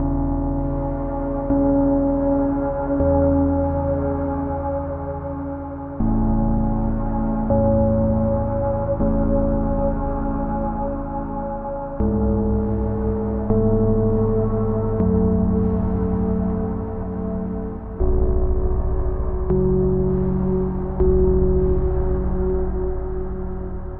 Tag: 80 bpm Ambient Loops Synth Loops 4.04 MB wav Key : E